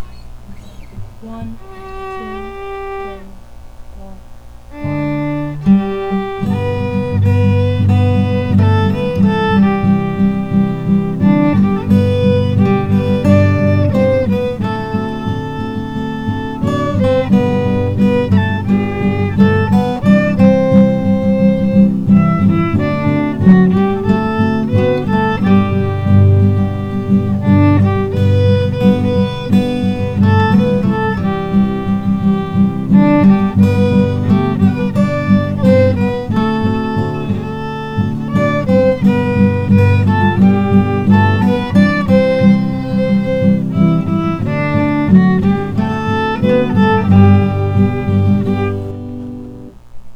I did a duet with myself! Sorry about the out-of-tune awful-ness in the first verse.